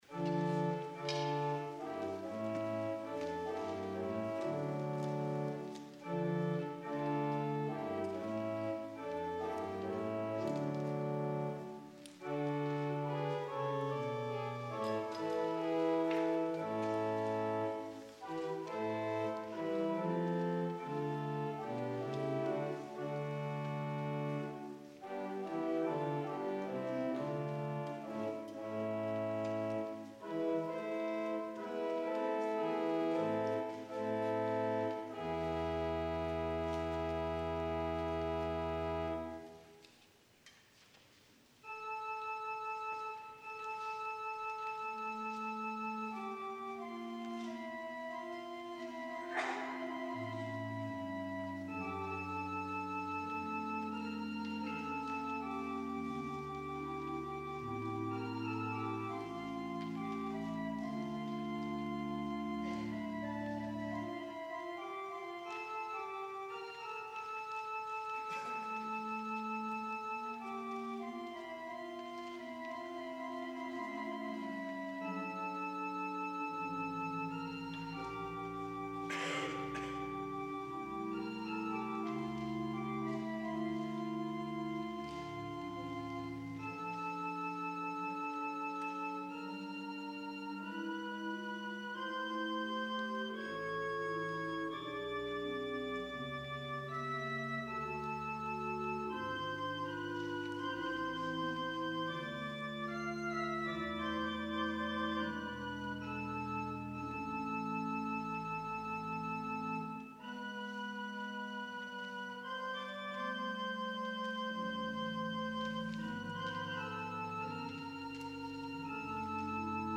ORGAN VOLUNTARY: The Old Year Has Passed (Orgelbüchlein) Johann Sebastian Bach, 1685-1750
organ